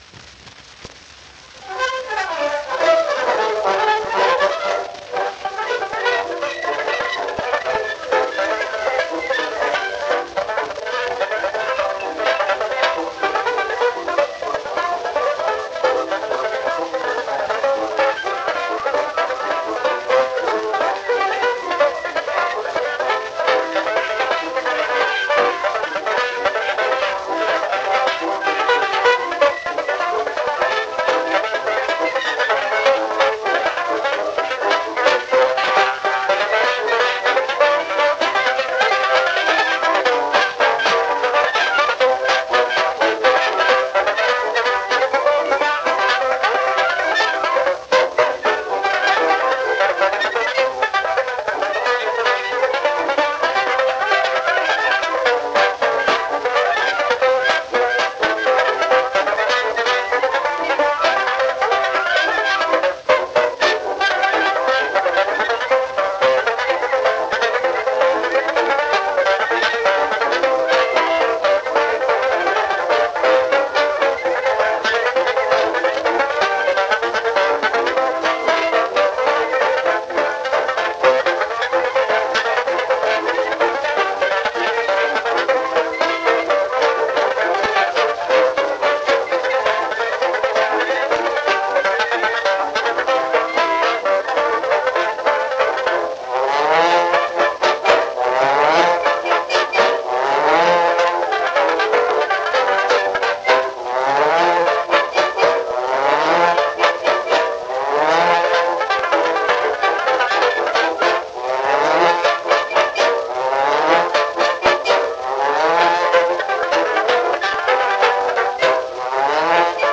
Banjo mit Bläserbegleitung.